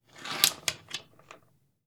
Lock Chain Open Sound
household